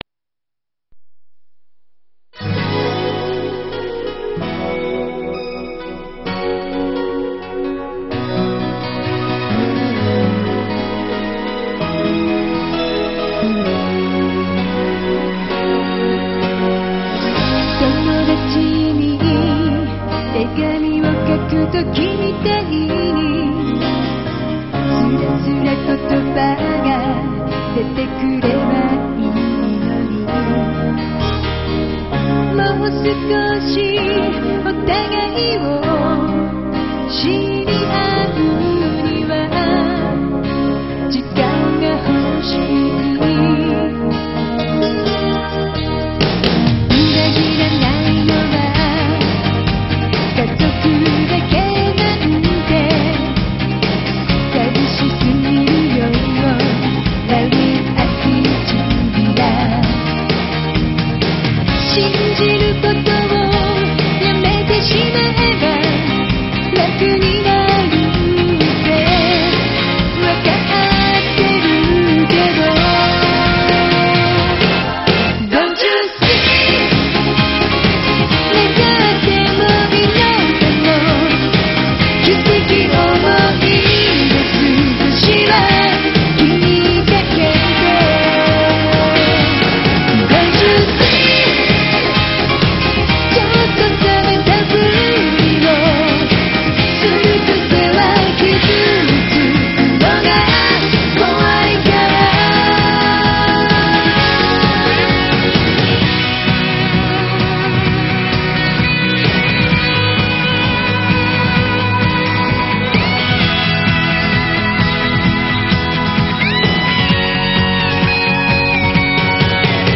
ending song, but only in Mp2 form, with words.